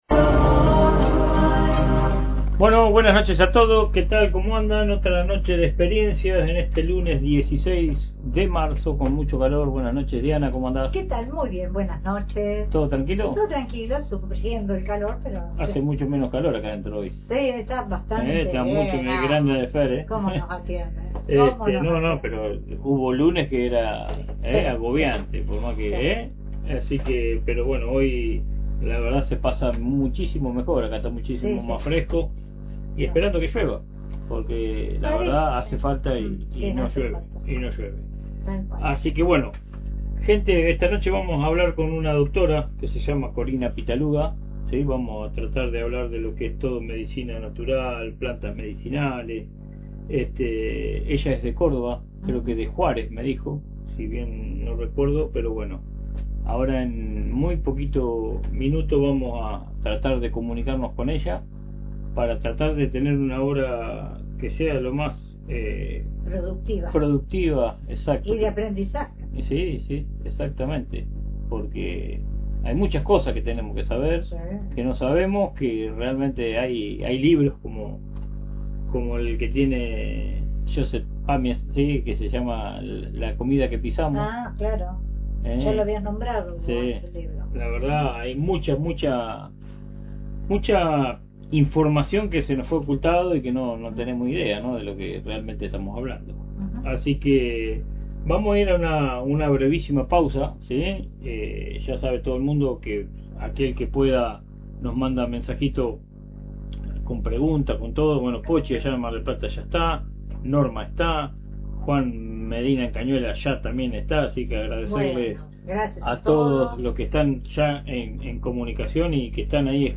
Hoy la entrevistada es